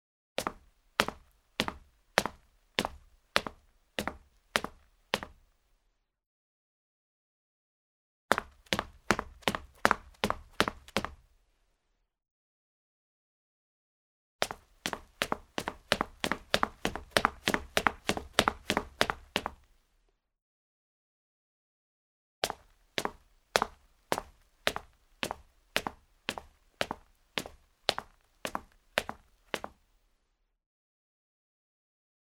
Tiếng đi bộ MP3